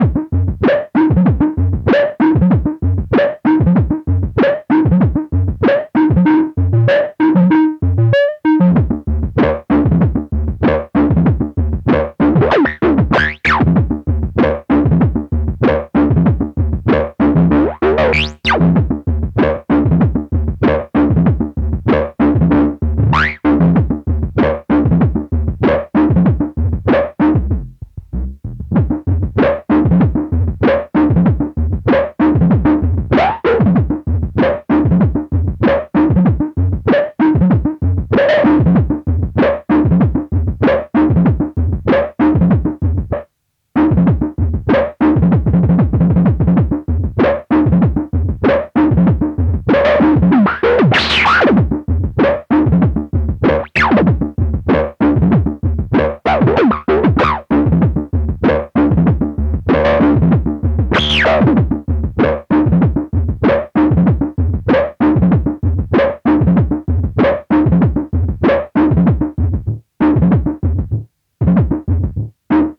Most analog sounding analog synth